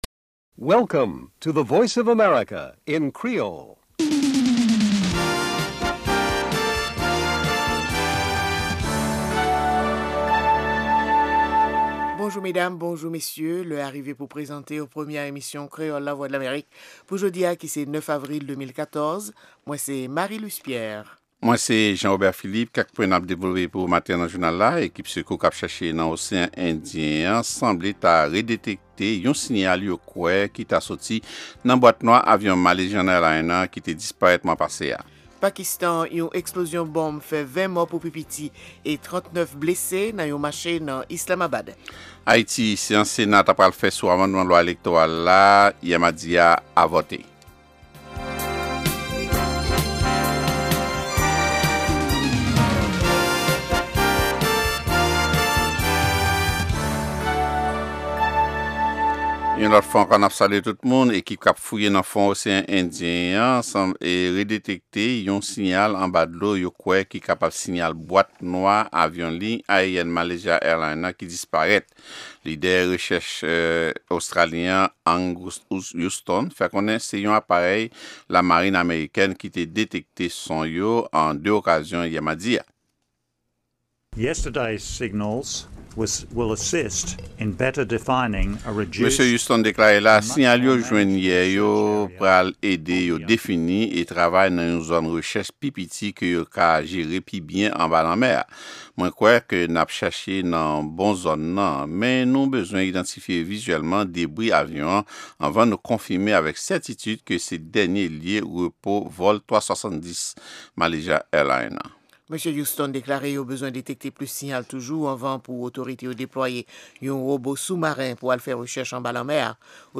Se premye pwogram jounen an ki gen ladan dènye nouvèl sou Lèzetazini, Ayiti ak rès mond la. Pami segman yo genyen espò, dyasporama ak editoryal la.